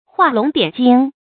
注音：ㄏㄨㄚˋ ㄌㄨㄙˊ ㄉㄧㄢˇ ㄐㄧㄥ
畫龍點睛的讀法